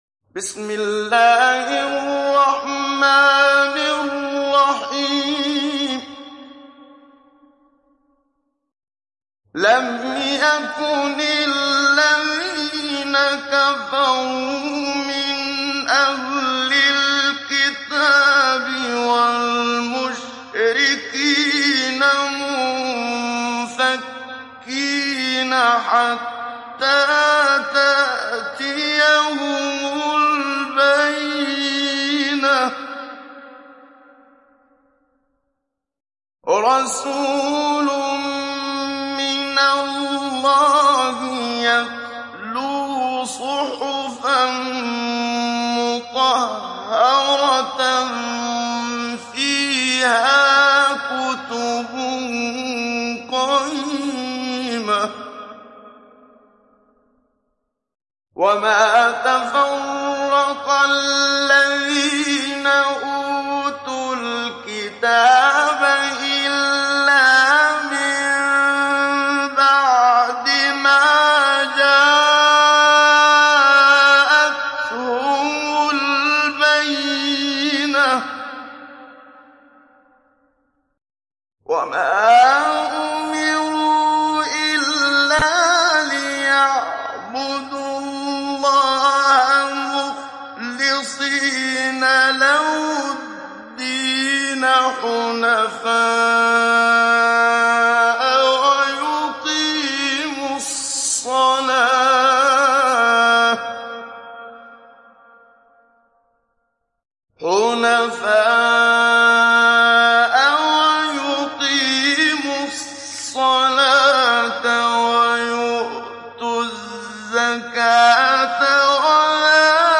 Télécharger Sourate Al Bayyina Muhammad Siddiq Minshawi Mujawwad